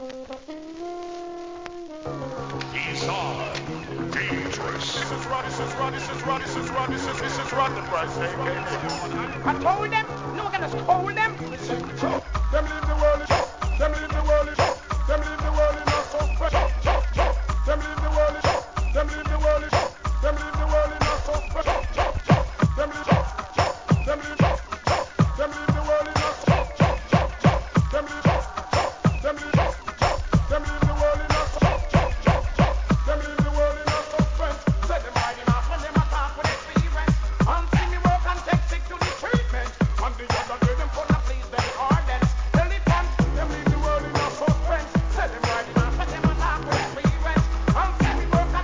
REGGAE
当時流行のMEGA MIX STYLE!!